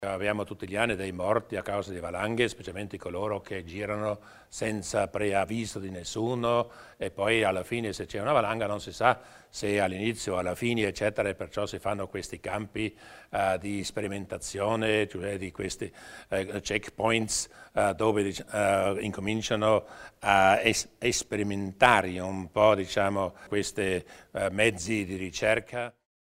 Il Presidente Durnwalder illustra le nuove iniziative in tema di sicurezza in montagna